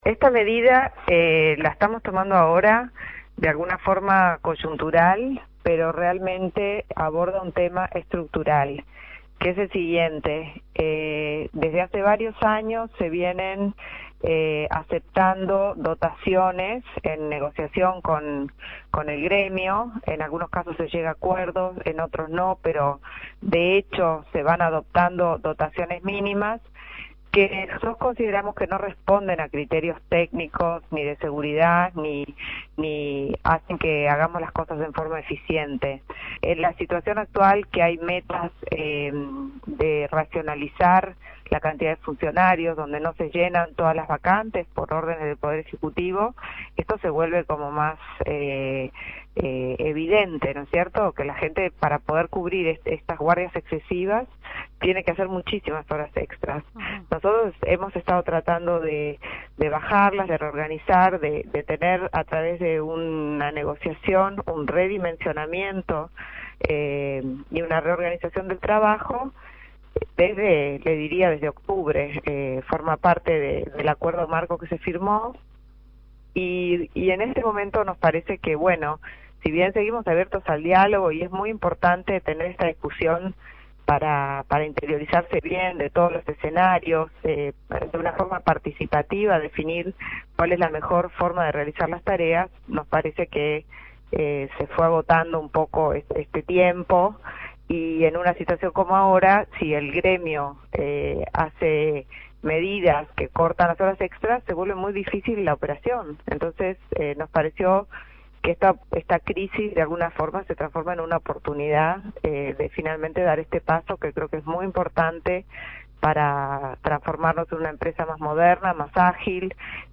Ancap resolvió este lunes el reordenamiento del trabajo en la empresa, redefiniendo las guardias, turnos y formas de operación de forma de que el ente no quede sujeto a la realización de horas extras de trabajadores para el cumplimiento de sus cometidos. La presidenta de Ancap, Marta Jara, explicó que desde hace varios años se aceptan dotaciones mínimas de persona por área en negociación con el sindicato.